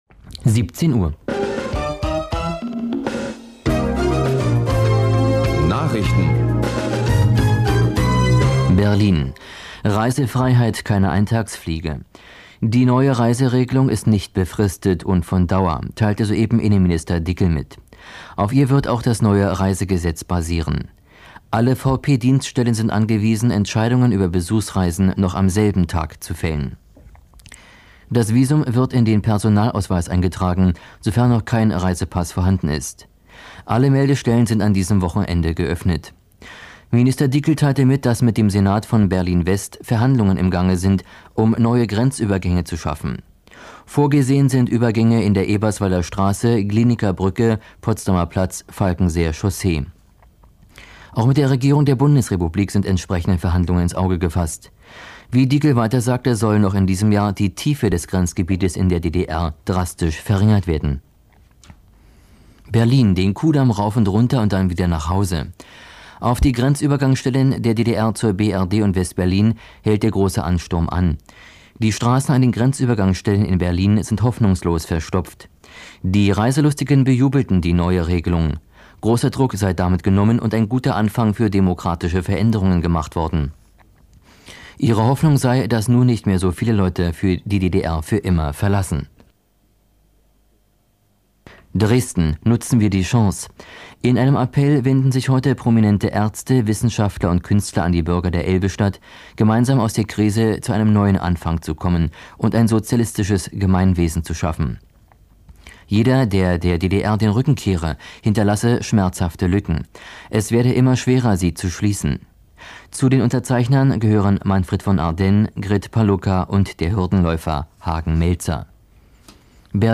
Dafür gibt es eine komplette Nachrichtensendung vom 10. November.